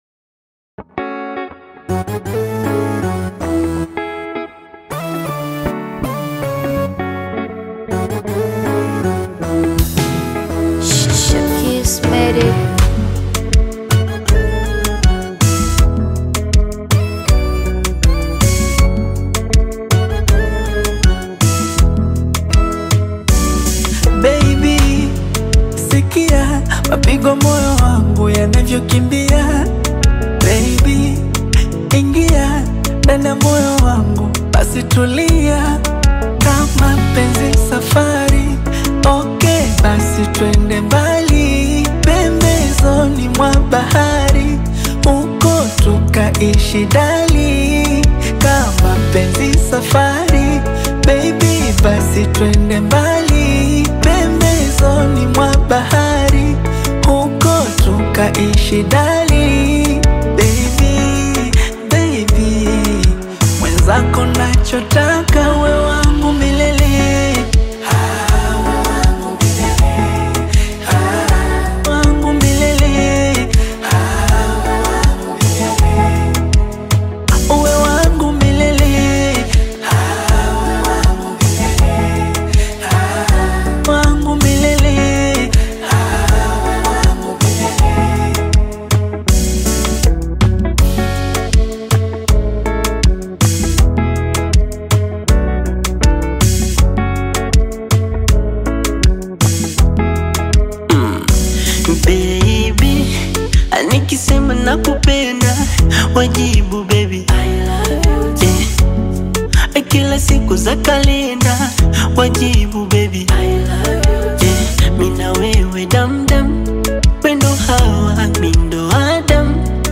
creating a vibrant and engaging musical experience.